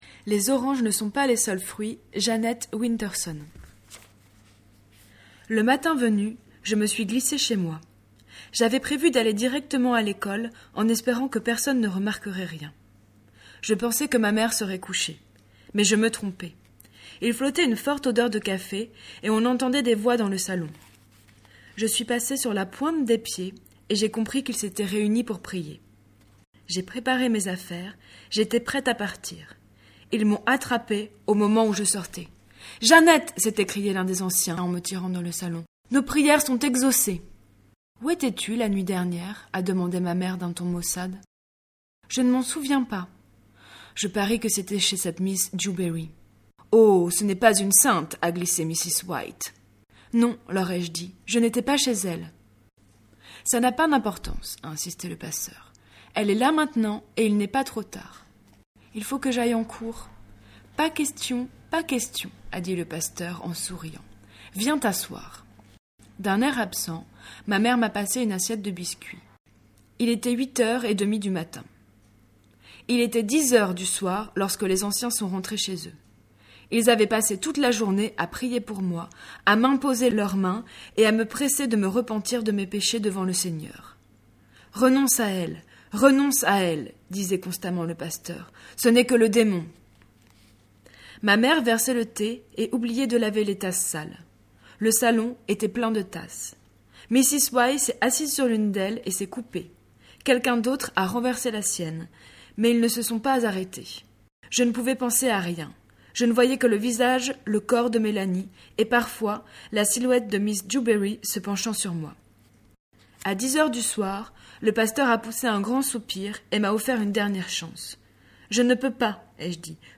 lectures gay lesbienne trans à écouter __________________________17 mai 2014 - Poitiers